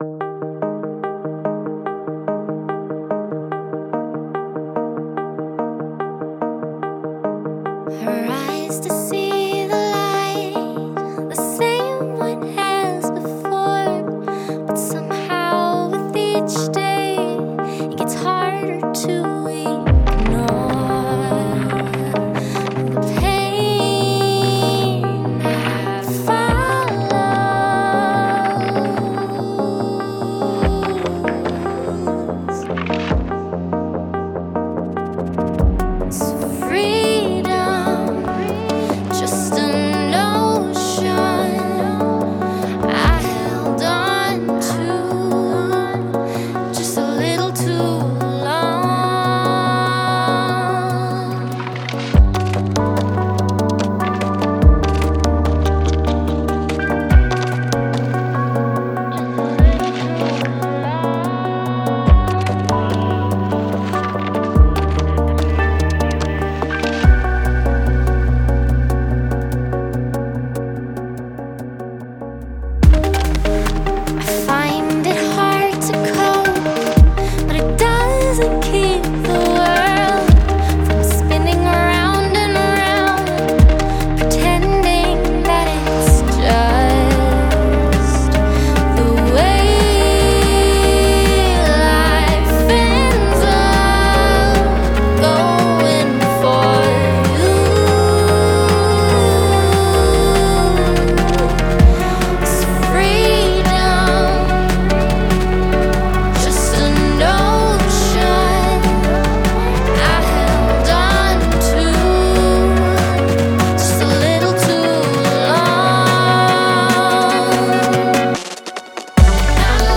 Vocals
Acoustic Drums
Guitar